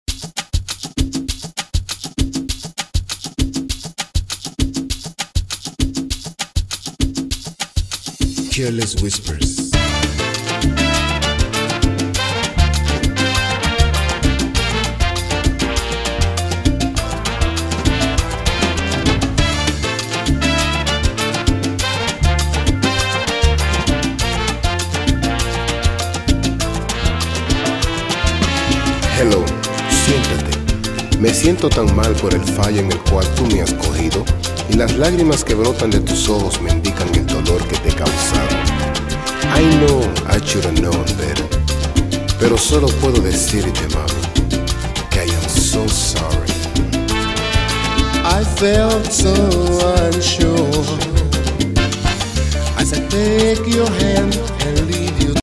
His style is unique and electrifying.
DJ